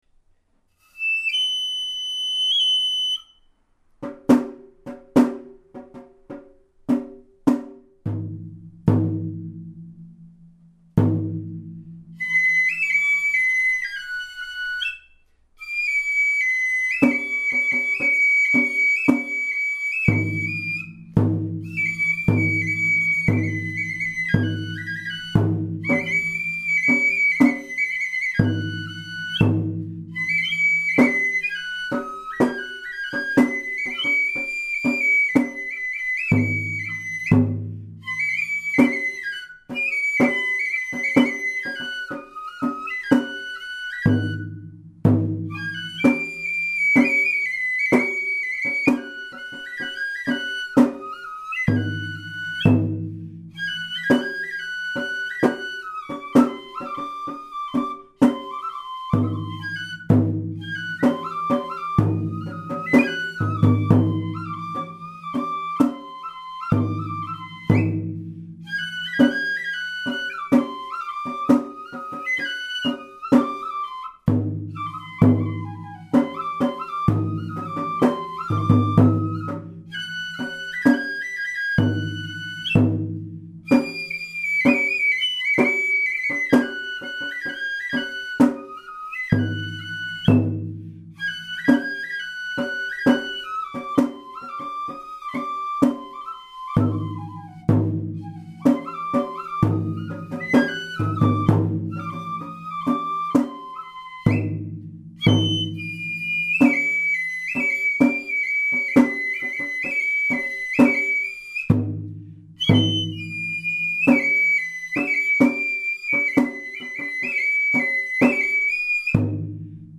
なお、笛の方はとっても上手ですが、太鼓（実は大部分が私）は数箇所間違っております。
ただ、音源に合せて吹きやすいように、今回は高音の部分から全部吹いてもらいました。